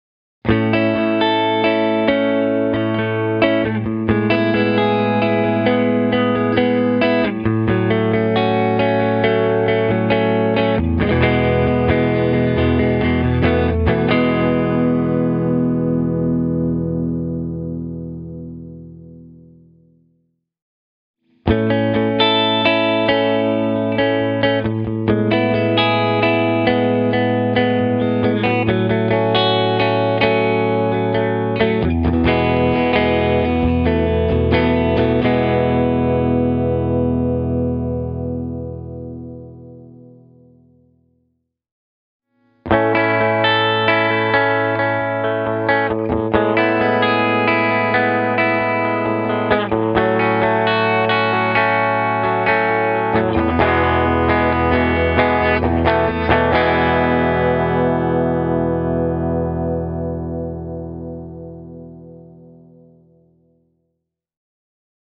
The Smear-model features a healthy attack coupled with long and even sustain.
I really like Hagström’s vintage-voiced Custom 58-humbuckers, because their moderate output level leaves enough room for the guitar’s acoustic clarity and dynamics to shine through: